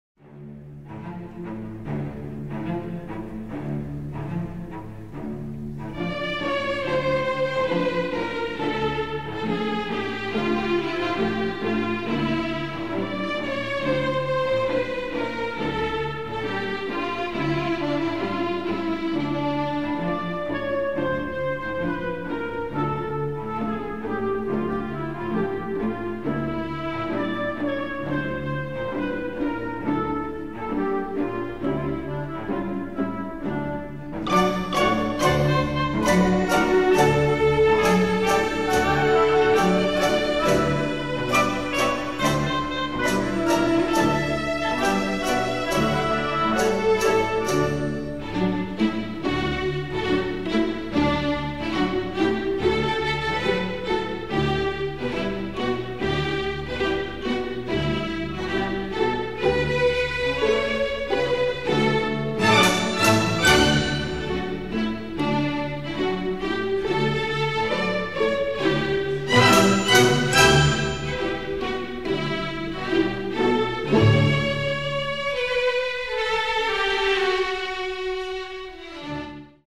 Palacio de la Música
Gala vocal Ópera Carmen